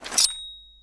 Knife Wield.wav